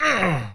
dfury_grunt2.wav